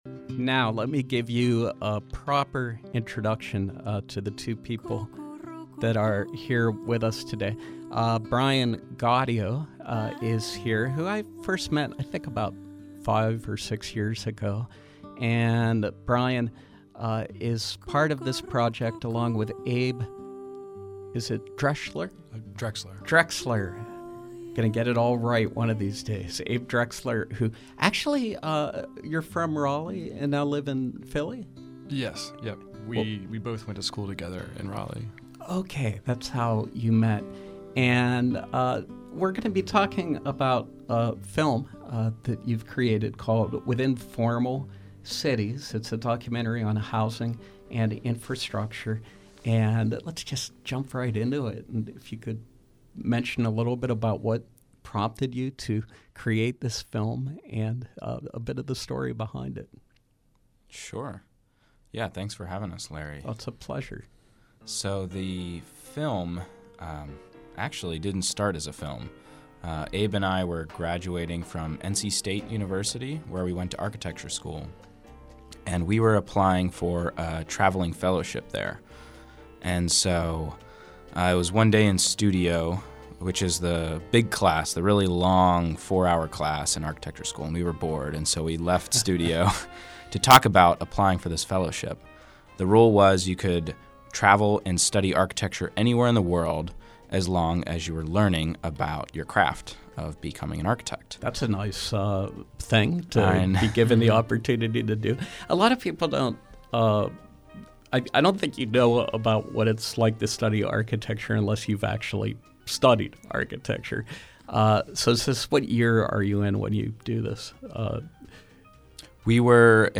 Interview: Within Formal Cities